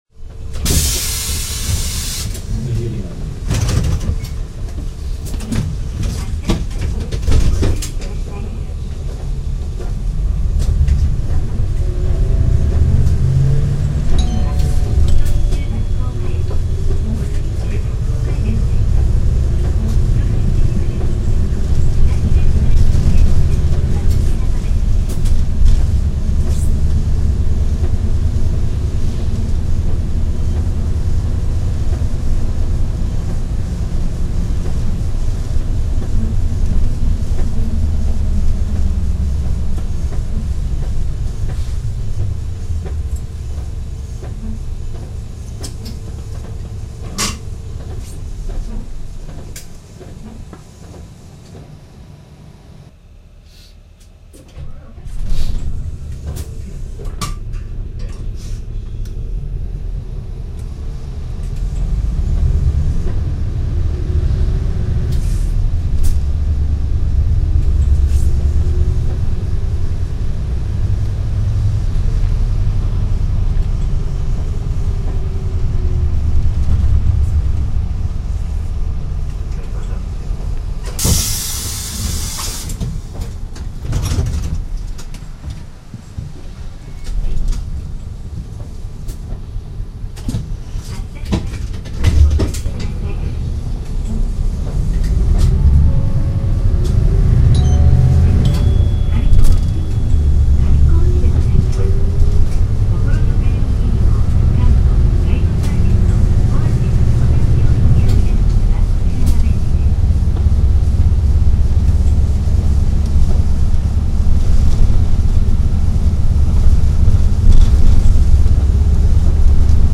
コレにより、路線バスとは思えない荘厳な重低音を轟かせて走行するシーンが見られるようになりました。
ＫＣ−ＬＶ３８０Ｌ リアビュー　長１６系統　西葛原〜御所見中学校前・杉久保〜高野付近 （３．７７ＭＢ）